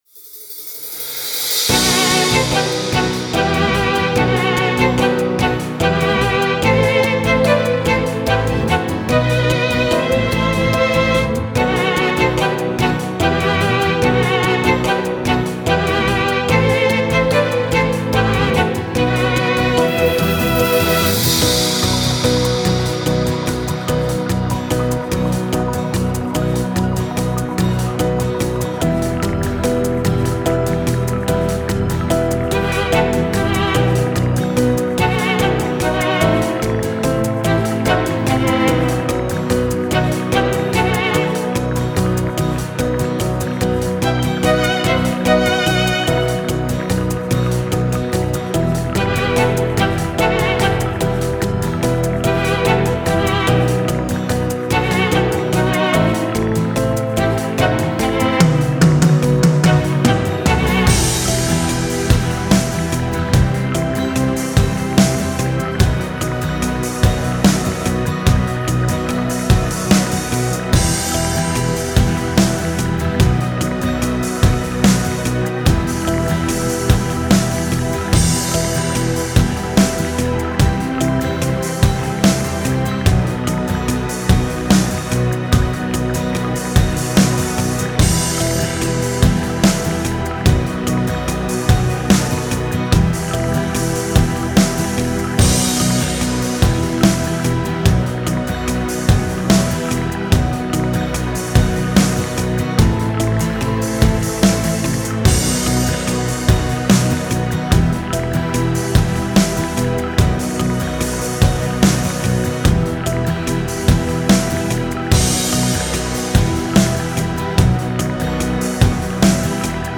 ניגון דבקות3.mp3